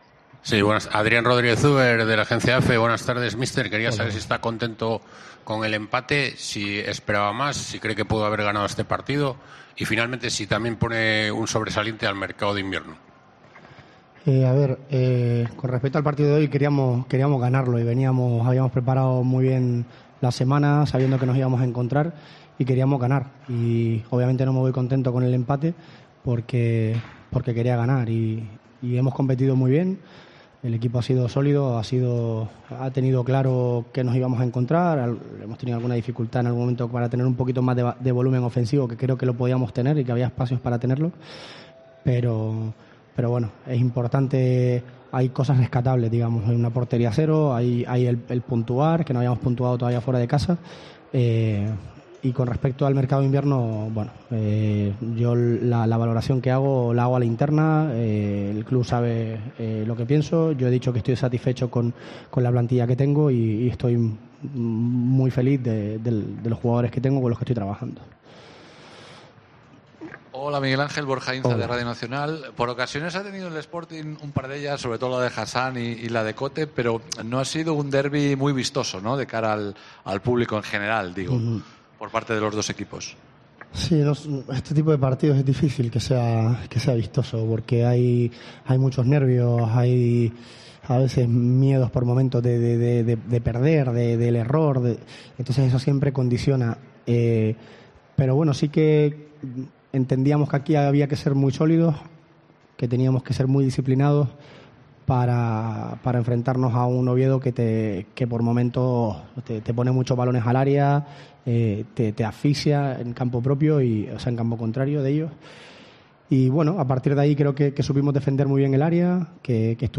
Deportes COPE Asturias RUEDA DE PRENSA